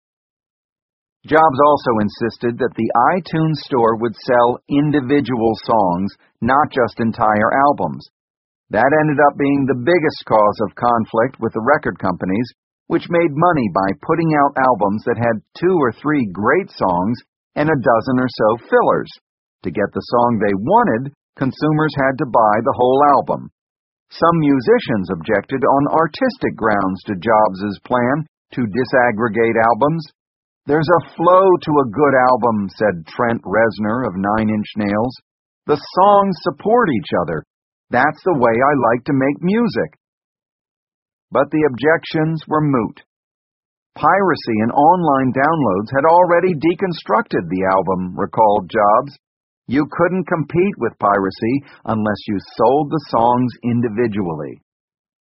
在线英语听力室乔布斯传 第524期:华纳音乐(6)的听力文件下载,《乔布斯传》双语有声读物栏目，通过英语音频MP3和中英双语字幕，来帮助英语学习者提高英语听说能力。
本栏目纯正的英语发音，以及完整的传记内容，详细描述了乔布斯的一生，是学习英语的必备材料。